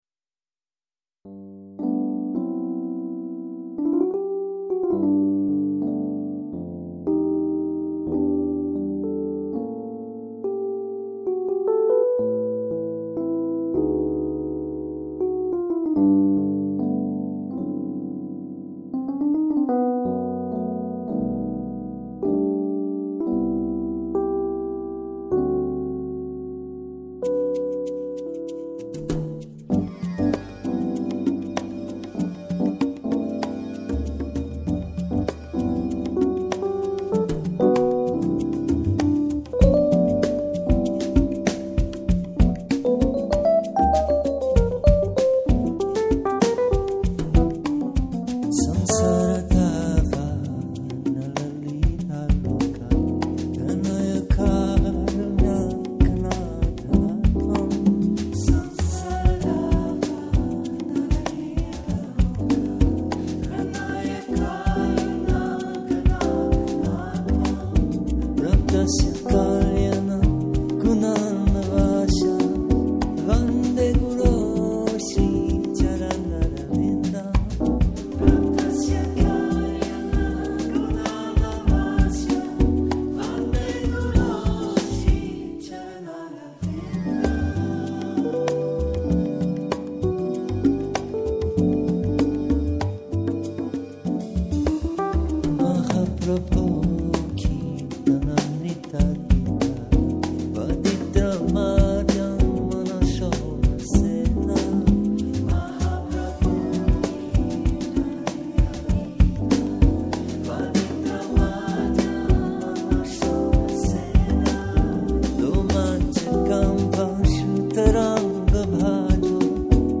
C’est dans une symphonie de chants de mantras jazzés